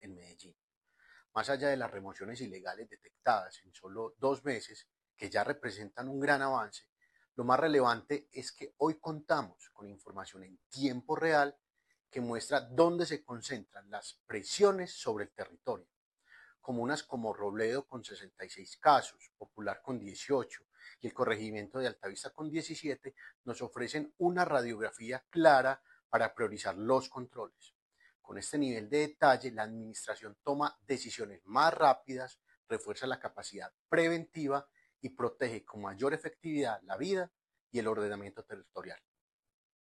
Declaraciones del subsecretario de Control Urbanístico, Carlos Trujillo
Declaraciones-del-subsecretario-de-Control-Urbanistico-Carlos-Trujillo.mp3